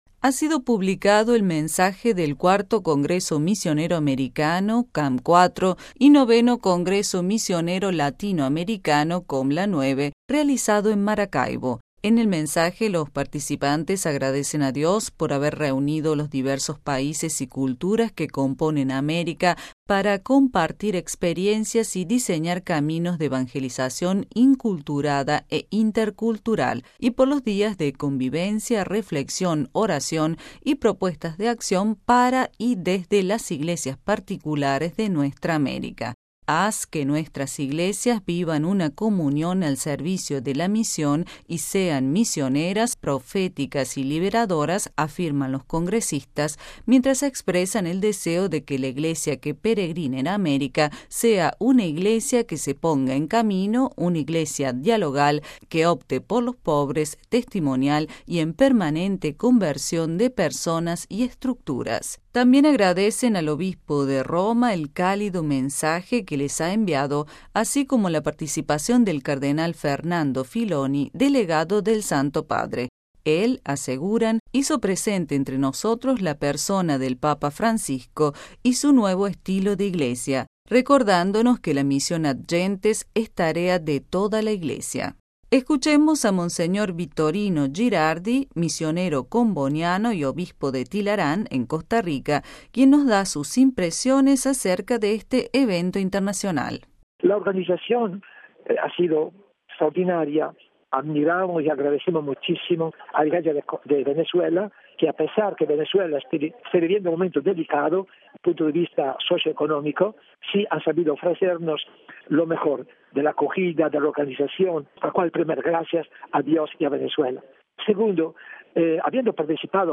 Escuchemos a Mons. Vittorino Girardi, misionero comboniano y Obispo de Tilarán en Costa Rica, quien nos da sus impresiones acerca de este evento internacional: RealAudio